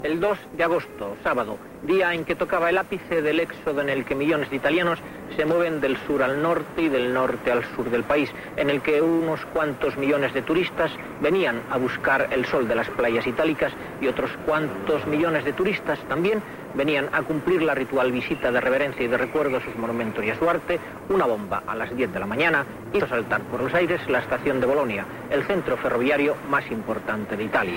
Crònica de l'atemptat terrorista a l'estació de trens de Bolònia a Itàlia
Informatiu